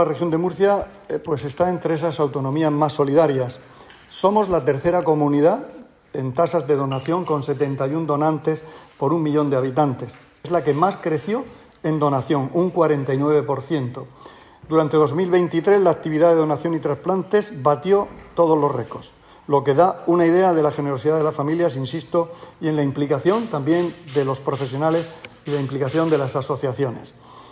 Declaraciones del consejero de Salud, Juan José Pedreño, sobre la actividad de donación y trasplantes de órganos en la Región en 2023.